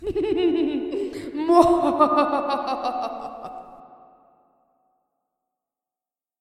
女孩邪恶的笑声 " 邪恶的笑声 7
描述：来自Wayside School的Sideways Stories的录制带来了邪恶的笑声。混响补充道。
Tag: 闲扯 笑声 女孩 女性 邪恶的 女人